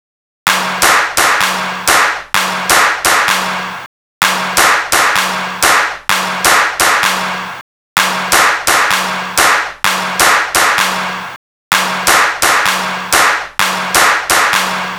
Session 11 - Claps.wav